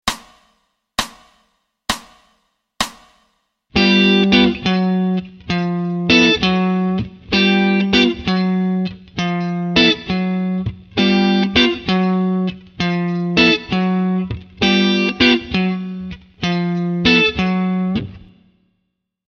The first example below pits these two voicings over a steady quarter note bassline—a moving bass note stated on each beat (count: “one, two, three, four”). Meanwhile, these chords in this figure are “popped” in the following eighth-note syncoptation (attack points italicized): “one-and, two-and, three-and, four-and.” Notice that the bassline in this igure is confined to the 5th string, and involves a descending/ascending move between the notes G-F#-F. In this case, F# is a chromatic passing tone (i.e., note outside of G Mixolydian), simply used to bridge the gap between two G7 (G-B-D-F) chord tones, “G” (the root; 10th fret, 5th string) and “F” (the b7; 8th fret, 5th string).
WalkBassLinesFig1(SLO).mp3